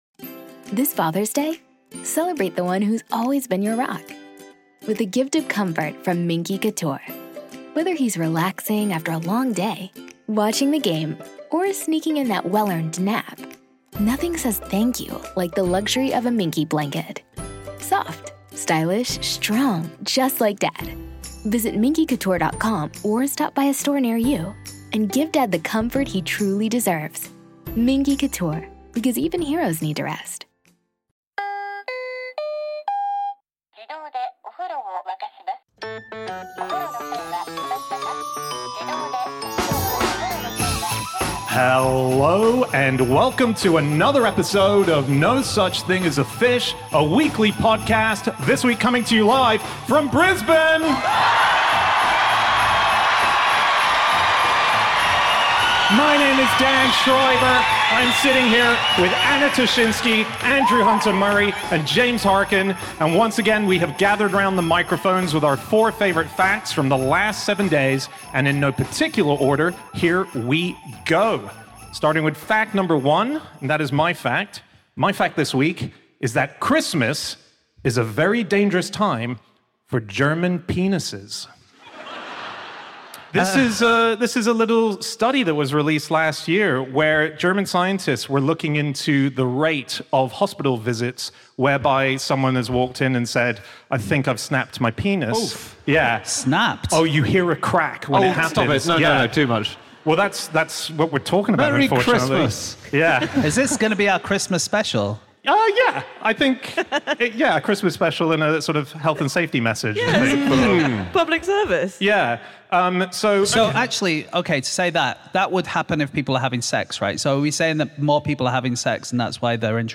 Live from Brisbane